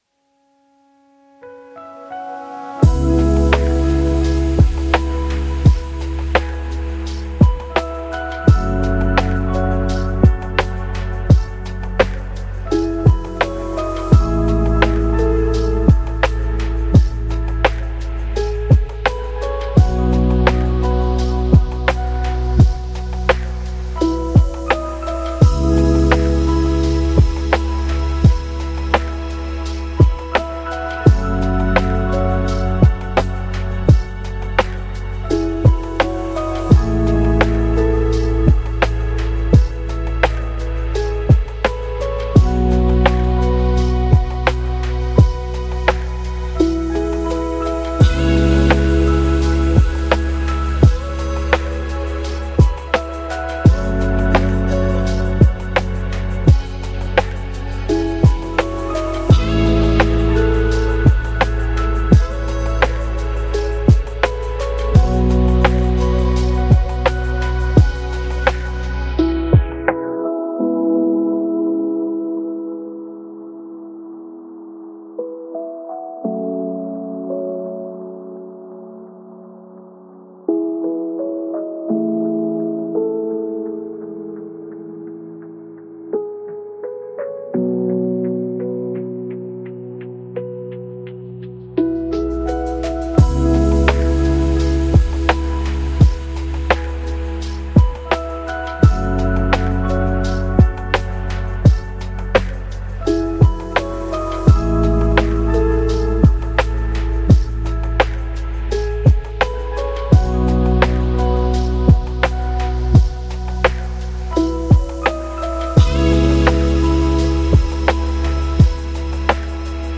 Lofi Chill